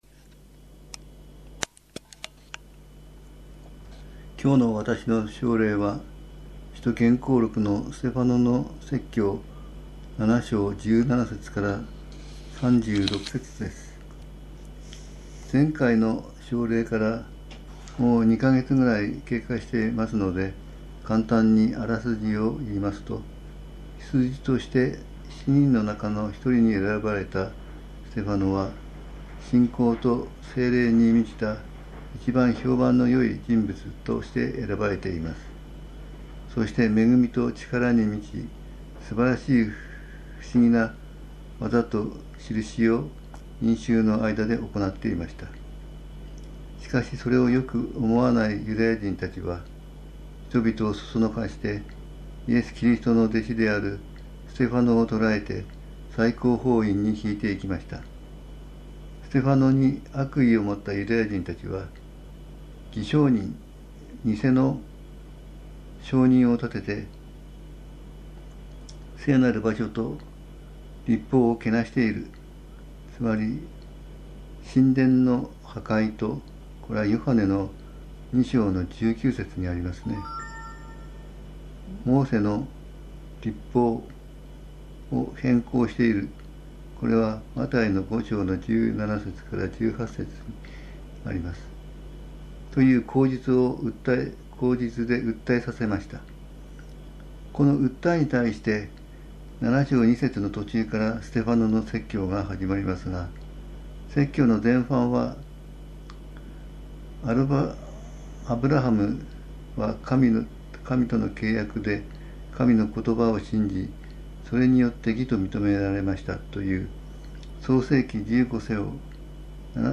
モｰセときょうだいたち(奨励の中で７章23節の五つ訳文比較をメッセ－ジに載せてあります) 宇都宮教会 礼拝説教